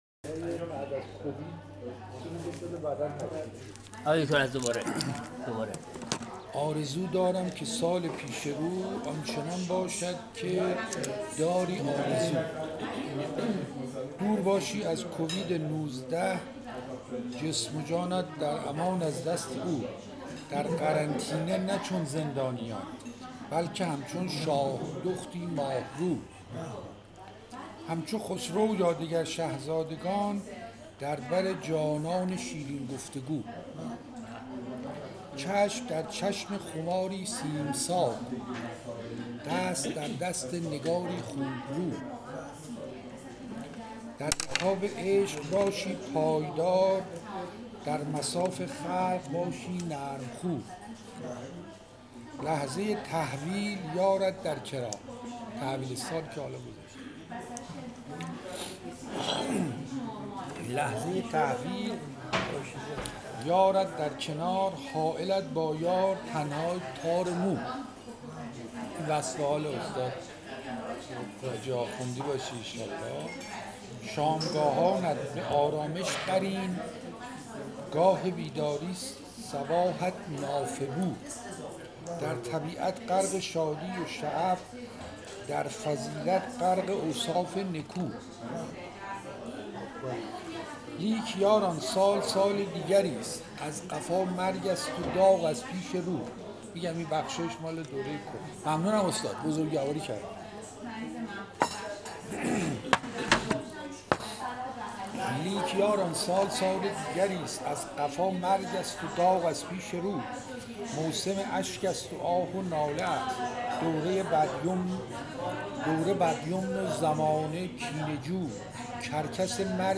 دورهمی صبحانه‌ی پیشکسوتان مجتمع بیمارستانی امام خمینی به محفلی ادبی و خاطره انگیز تبدیل شد.